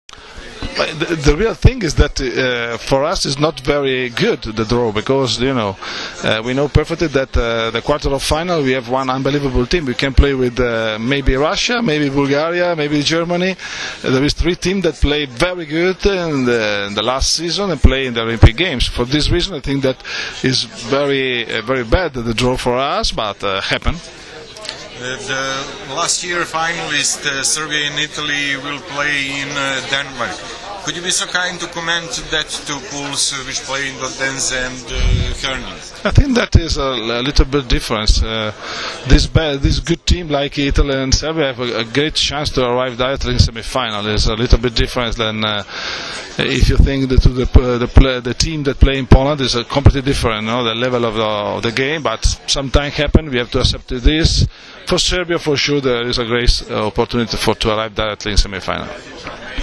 IZJAVA ANDREE ANASTAZIJA, SELEKTORA POLJSKE